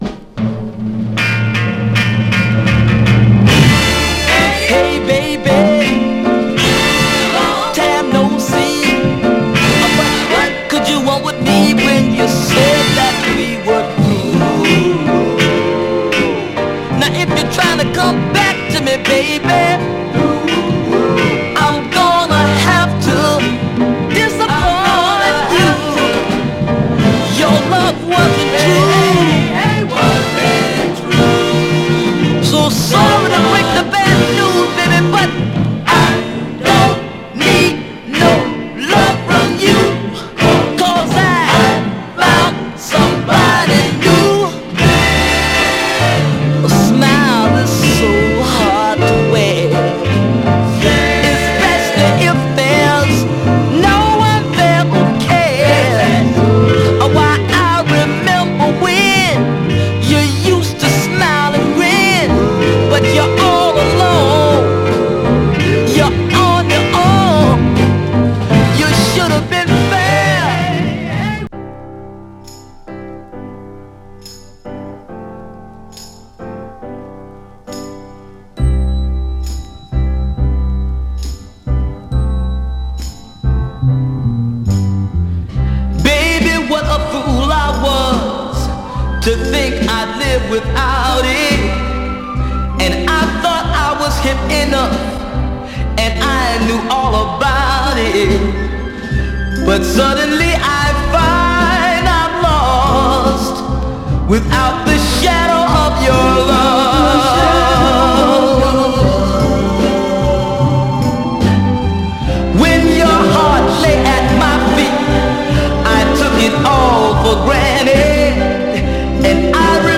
甘茶テイストな美しいスウィート・ソウル